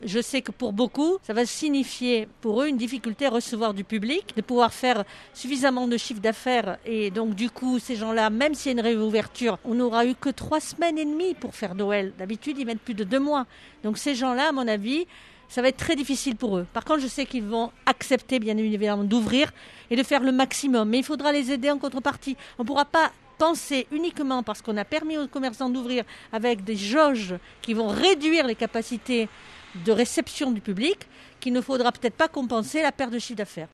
Reportage à Marseille